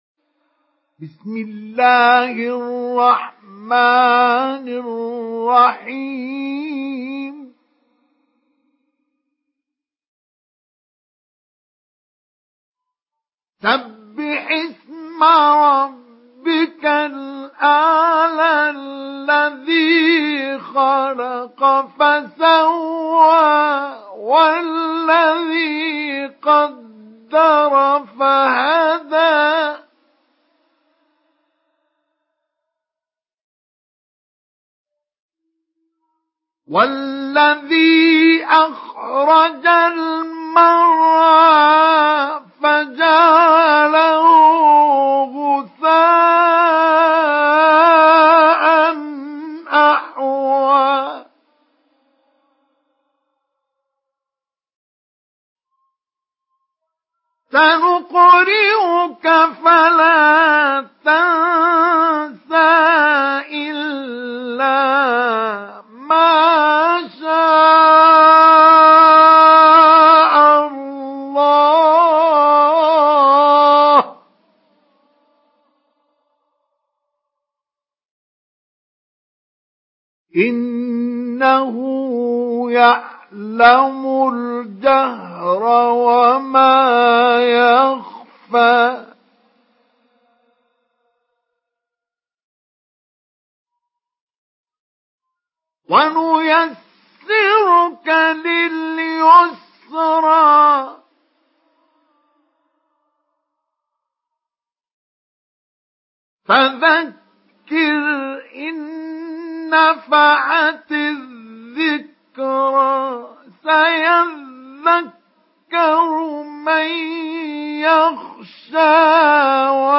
Surah আল-আ‘লা MP3 by Mustafa Ismail Mujawwad in Hafs An Asim narration.